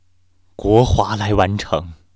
sad